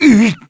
Gladi-Vox_Damage_kr_02.wav